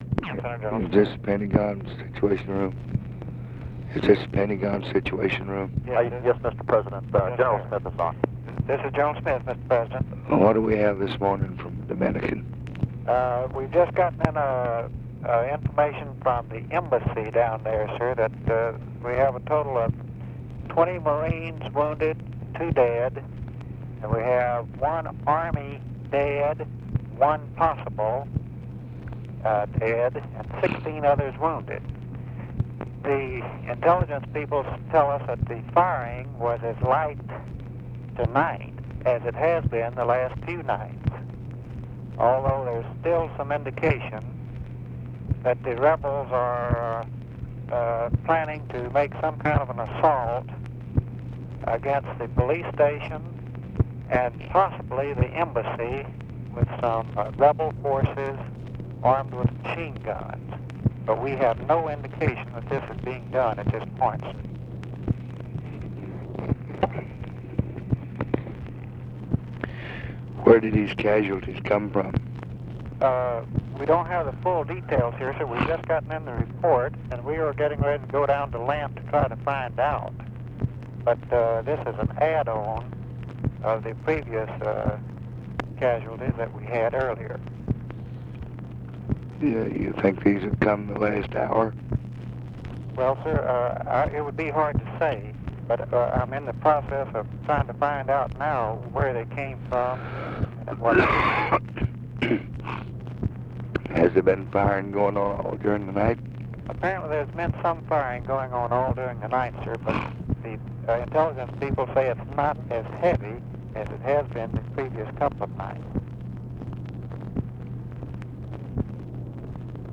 Conversation with PENTAGON COMMAND CENTER and OFFICE CONVERSATION, May 2, 1965
Secret White House Tapes